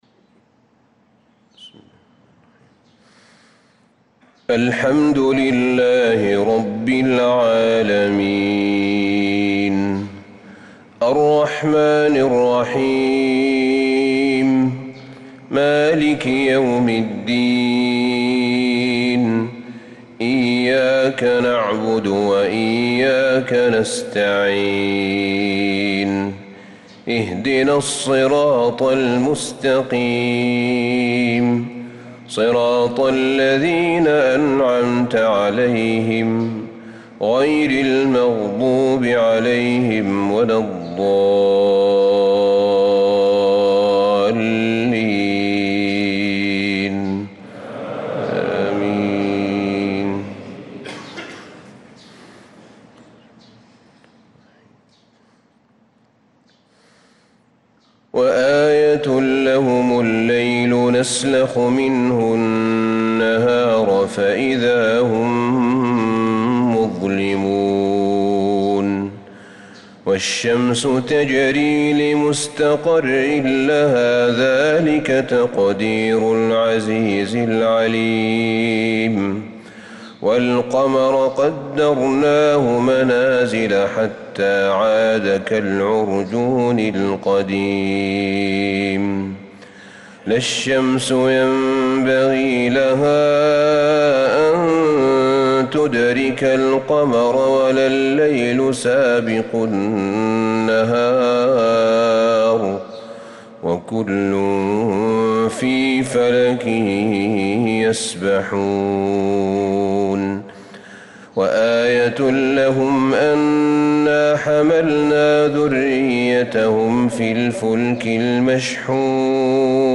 صلاة الفجر للقارئ أحمد بن طالب حميد 29 محرم 1446 هـ
تِلَاوَات الْحَرَمَيْن .